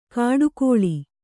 ♪ kāḍu kōḷi